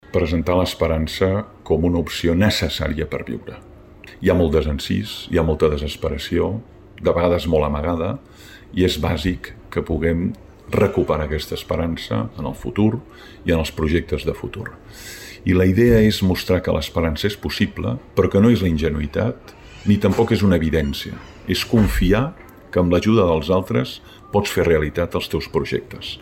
La Biblioteca Francesc Pujols s’ha omplert de lectors i amants de la filosofia amb motiu de la presentació del llibre Anatomia de l’esperança, la nova obra del pensador Francesc Torralba.
Francesc Torralba, autor 'Anatomia de l'esperança'